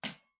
餐具的声音 " 小勺子4
Tag: 餐具